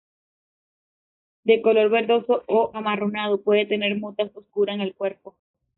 ver‧do‧so
/beɾˈdoso/